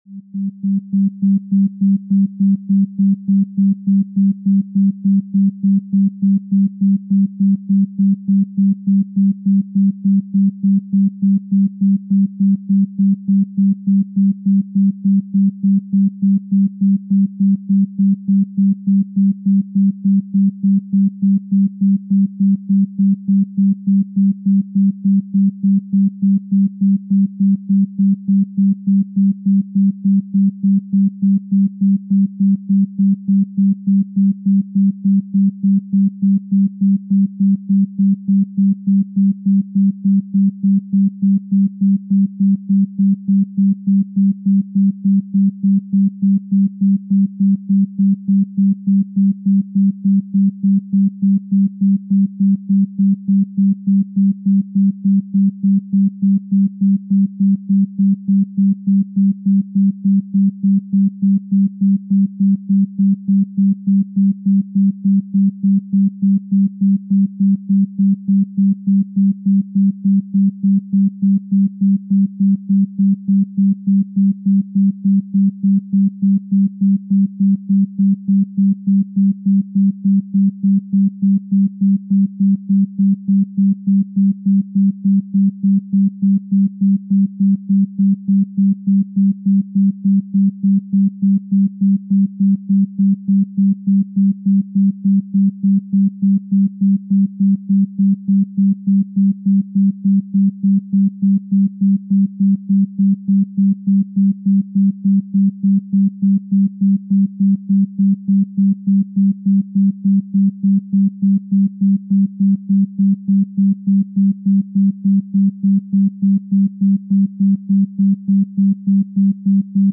Experience deep relaxation and enhance your sleep quality with this 3.4 Hz isochronic tone. This frequency is designed to promote restorative sleep and reduce stress, helping you wake up refreshed and rejuvenated. Close your eyes, and let the calming sounds guide you into a peaceful slumber.